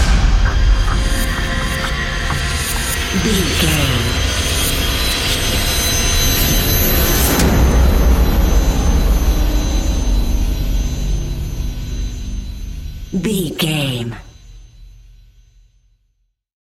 Ionian/Major
D♯
industrial
dark ambient
EBM
drone
synths